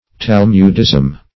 Search Result for " talmudism" : The Collaborative International Dictionary of English v.0.48: Talmudism \Tal"mud*ism\, n., n. The teachings of the Talmud, or adherence to them.